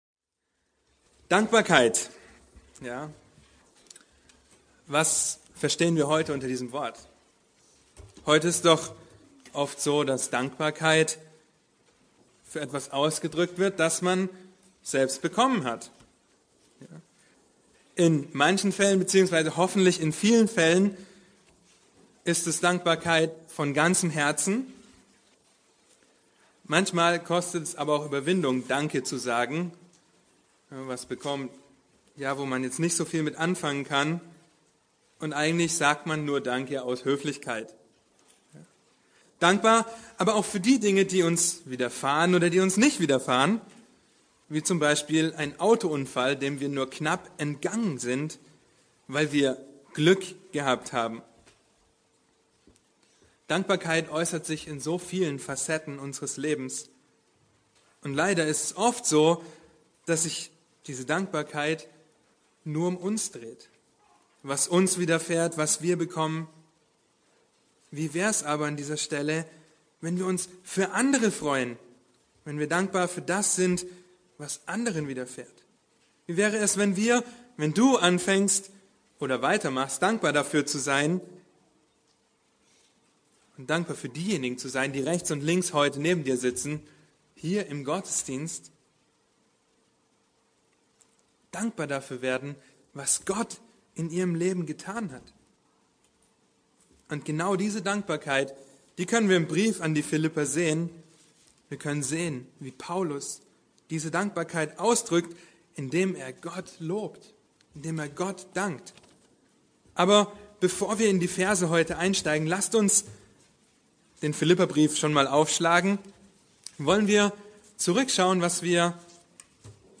Eine predigt aus der serie "Der Kern der Lehre Jesu."